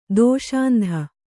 ♪ dōṣāndha